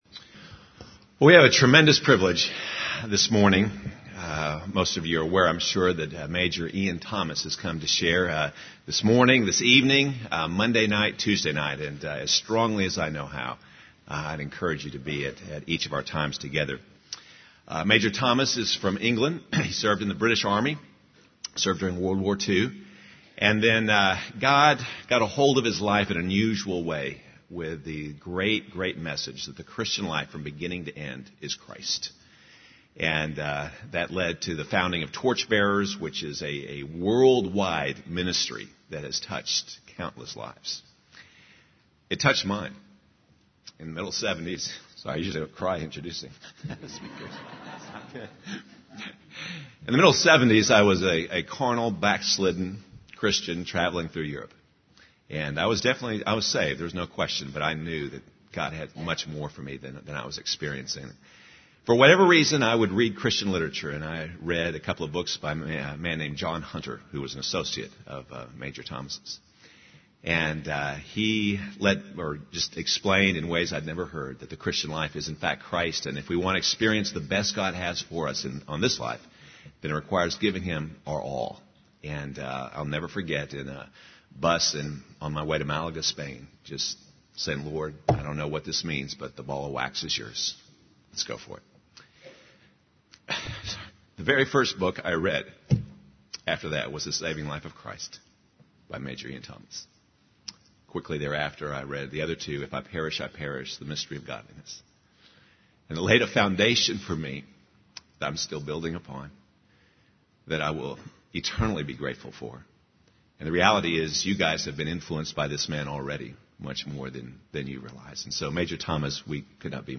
In this sermon, the preacher emphasizes the power of God's word and its ability to uphold all things in the universe. He highlights the importance of understanding the Gospel of Luke and the transformative event that occurred in the early church when Jesus was resurrected. The preacher also discusses the concept of identifying oneself with Jesus' death in order to experience his life every day.